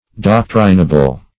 Search Result for " doctrinable" : The Collaborative International Dictionary of English v.0.48: Doctrinable \Doc"tri*na*ble\, a. Of the nature of, or constituting, doctrine.